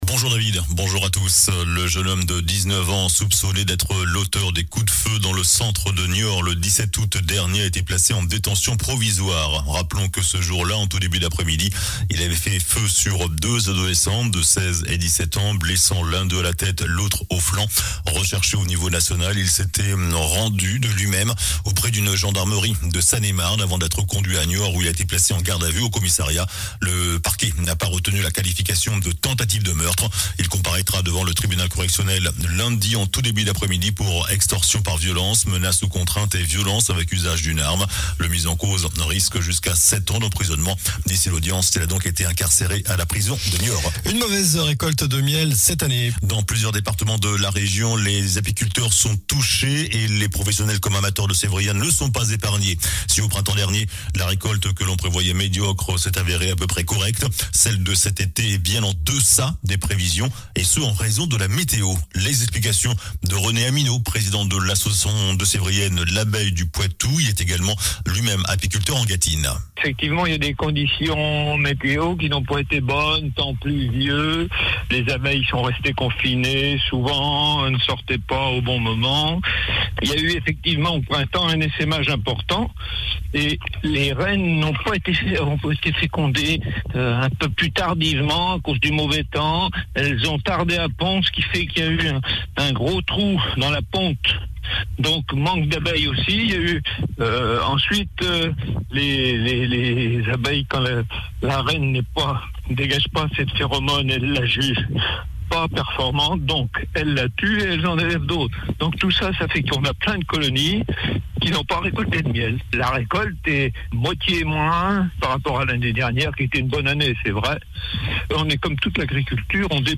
JOURNAL DU SAMEDI 28 AOUT